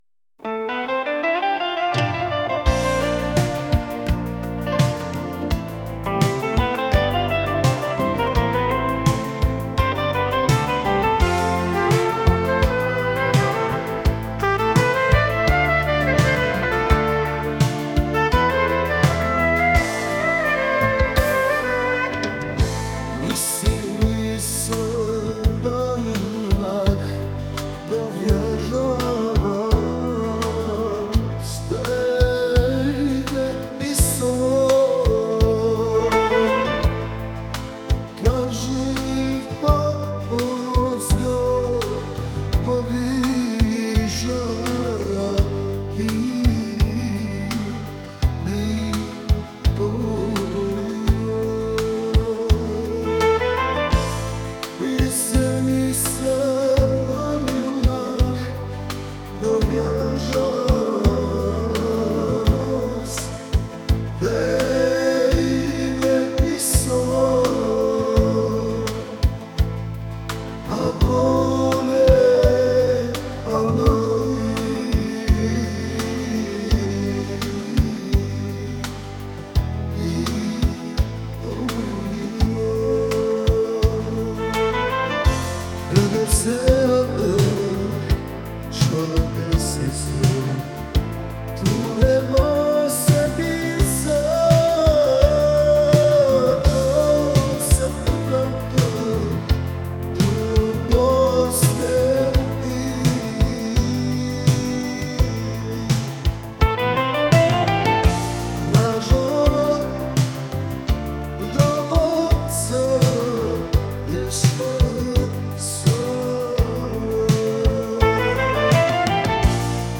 pop | romantic